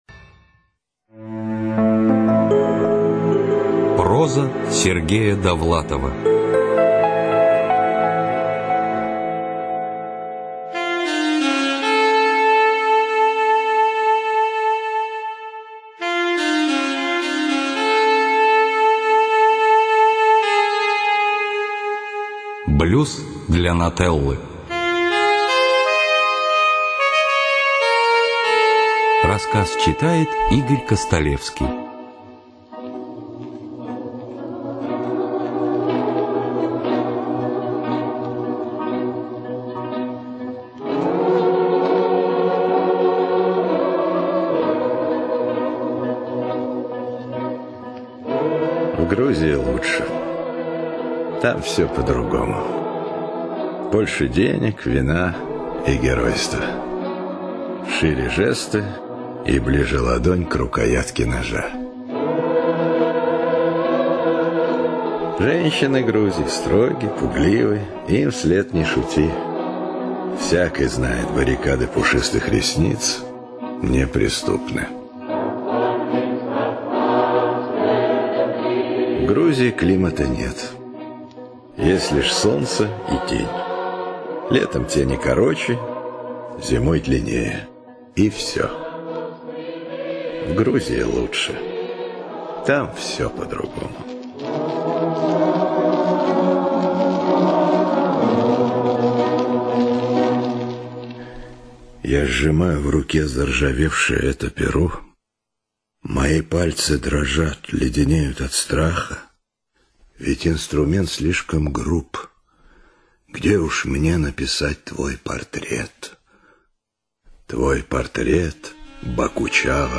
ЧитаютЛазарев А., Татарский В., Филиппенко А., Костолевский И., Фоменко Н.
ЖанрСовременная проза